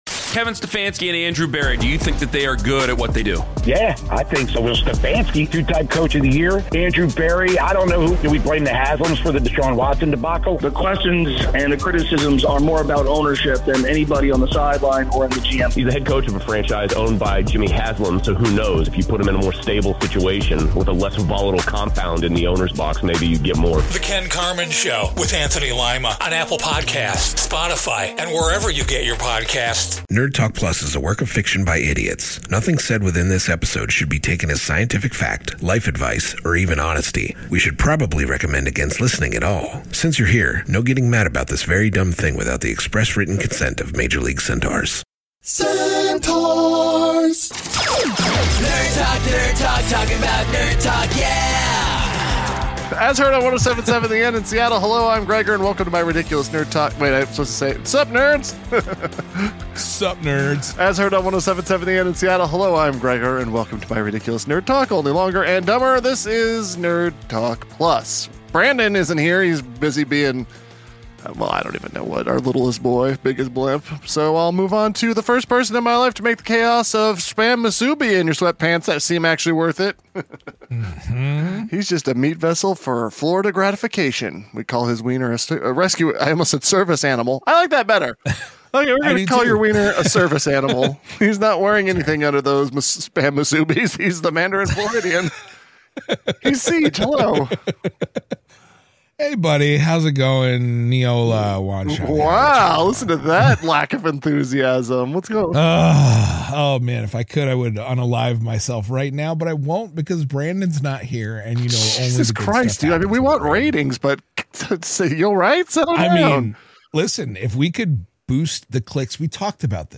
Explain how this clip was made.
As heard on 107.7 The End in Seattle